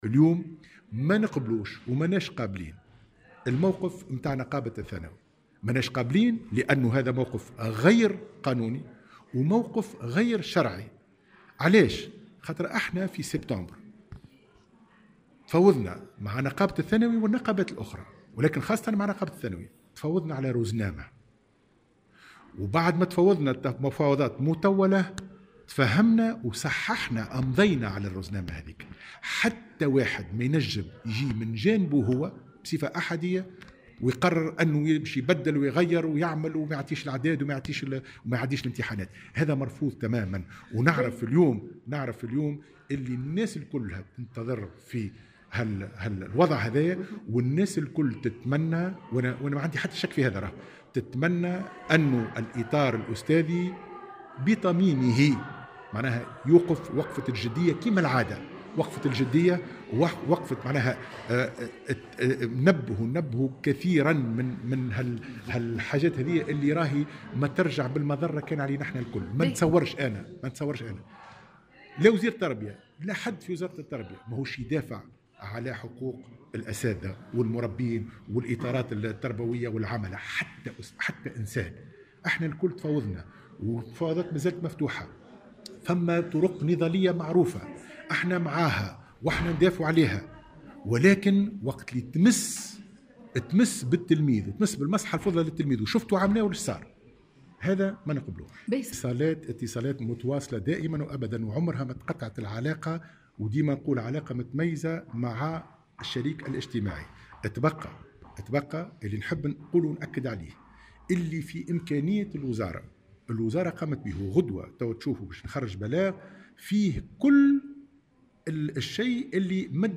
وقال في تصريح لمراسلة "الجوهرة أف أم" على هامش جلسة برلمانية، إنه تم الاتفاق مع الطرف النقابي منذ سبتمبر الماضي بخصوص رزنامة تم الامضاء عليها والتي تنص على ان يكون الاسبوع الحالي أسبوع الامتحانات والاسبوع المقبل يكون أسبوعا مغلقا للامتحانات، مؤكدا أن الاتصالات متواصلة بين الوزارة والطرف النقابي.